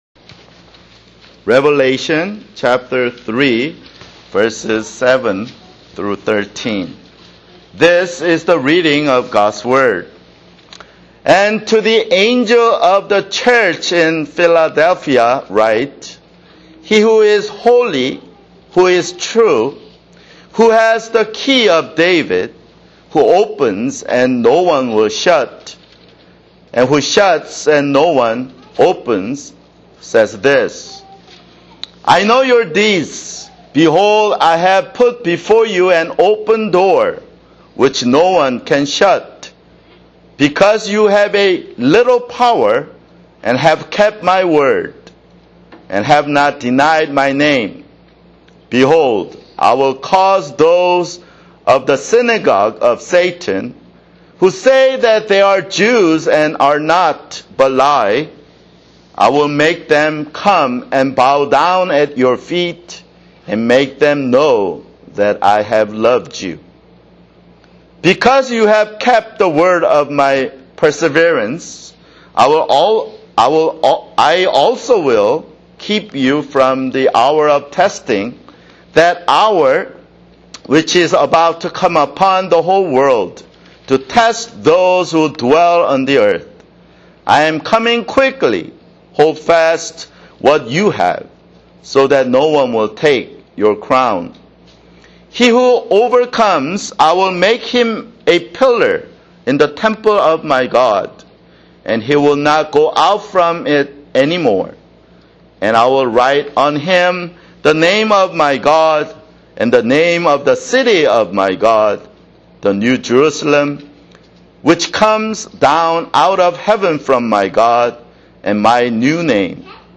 [Sermon] Revelation (17)